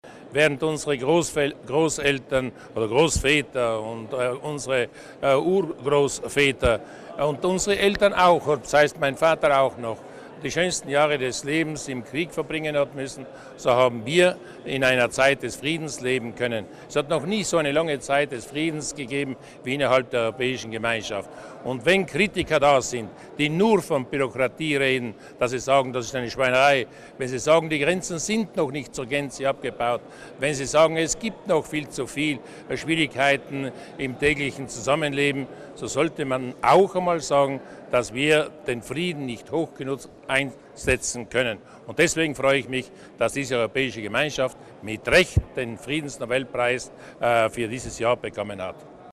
Landeshauptmann Durnwalder drück seine Freude über den Nobel-Preis für Europa aus